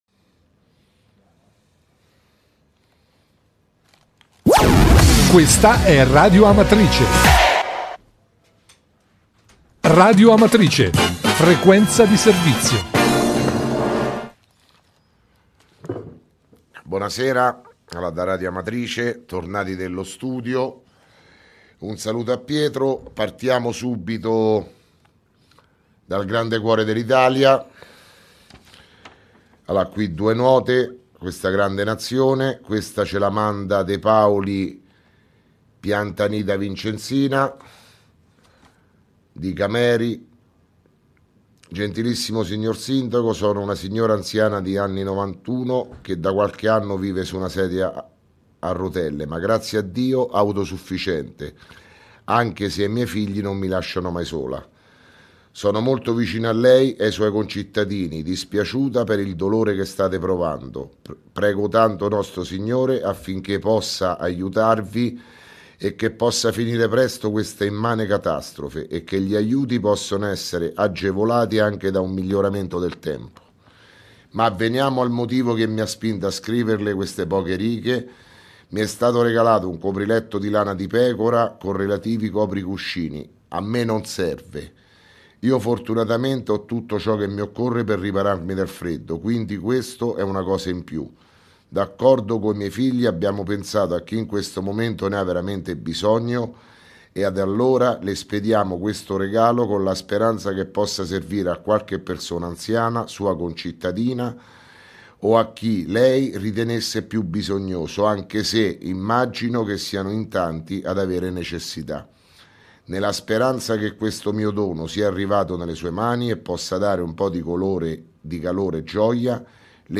Di seguito il messaggio audio del Sindaco Sergio Pirozzi, del 2 febbraio 2017.